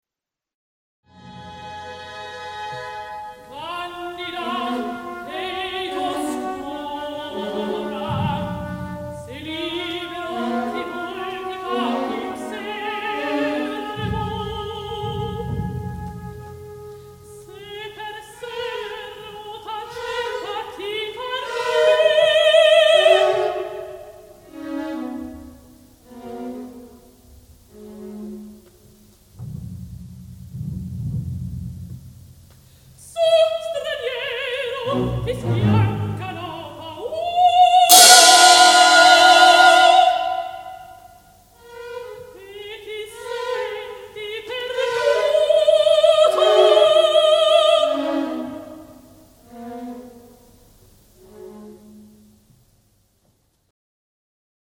oodles of appoggiaturas!